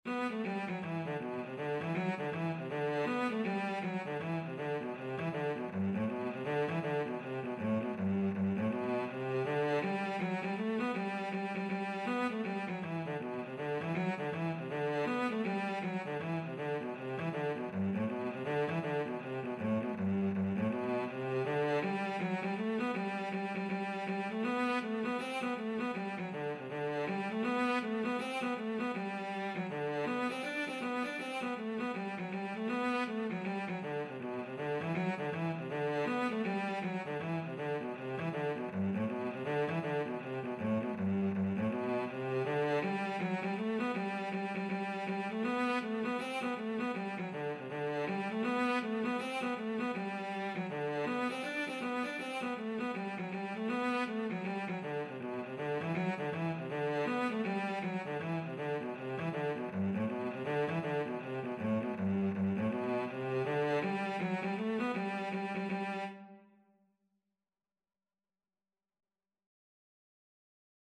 Cello version
4/4 (View more 4/4 Music)
G major (Sounding Pitch) (View more G major Music for Cello )
Cello  (View more Easy Cello Music)
Traditional (View more Traditional Cello Music)